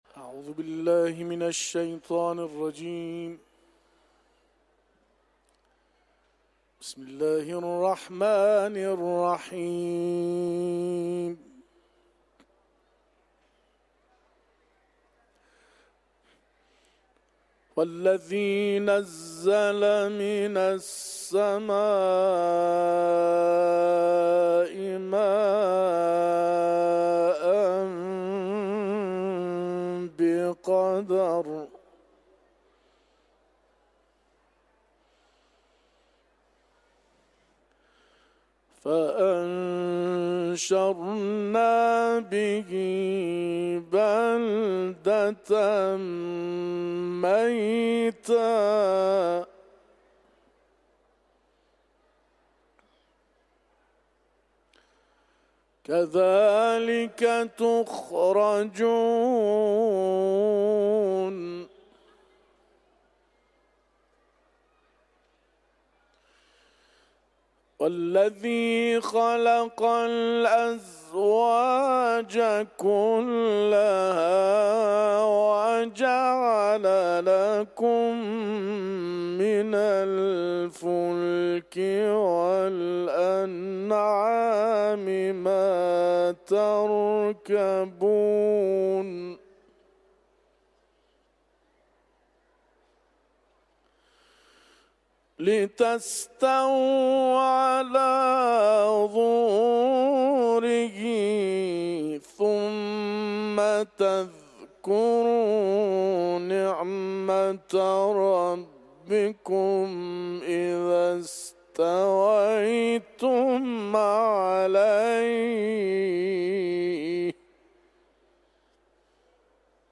در حرم مطهر رضوی
تلاوت قرآن ، سوره زخرف